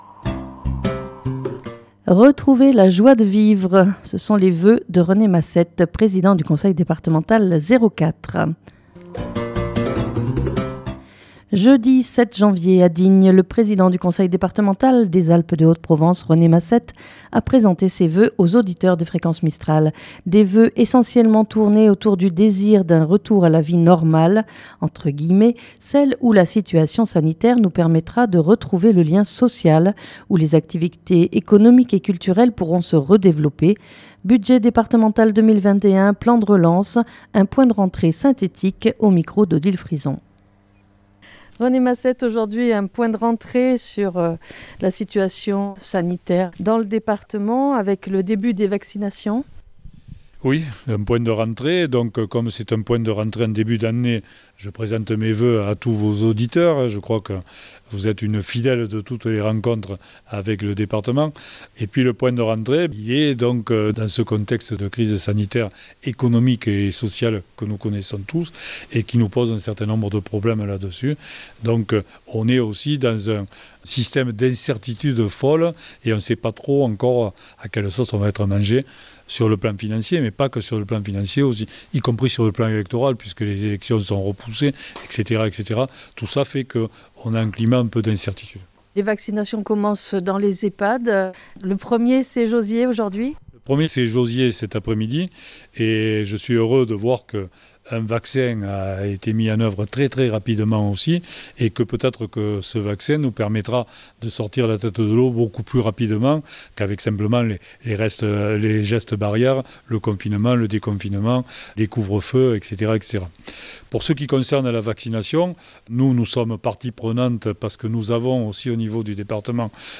Jeudi 7 janvier à Digne, le président du Conseil Départemental des Alpes de Haute Provence, René Massette a présenté ses voeux aux auditeurs de Fréquence Mistral. Des voeux essentiellement tournés autour du désir d'un retour à la "vie normale", celle où la situation sanitaire nous permettra de retrouver le lien social, où les activités économiques et culturelles pourront se redévelopper.